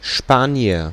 Ääntäminen
IPA : /ˈspæn.ɪʃ/